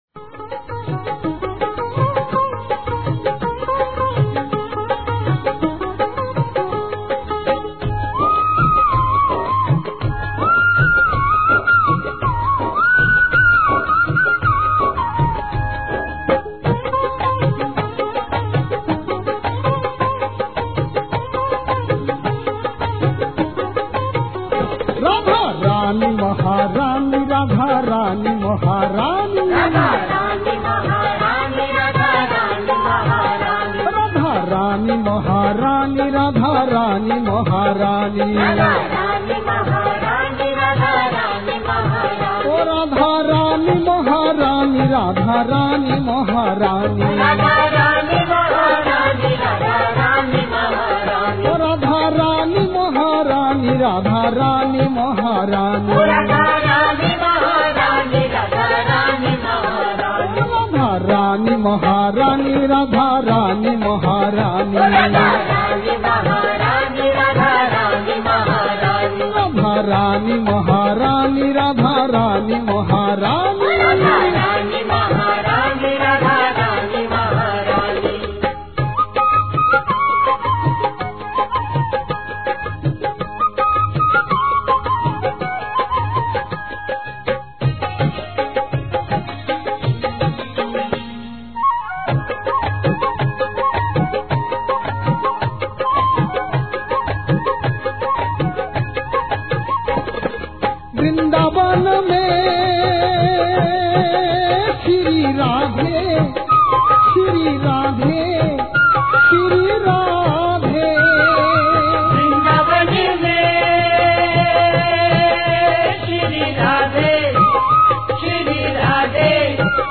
Live Kirtans from Sree Chaitanya Gaudiya Math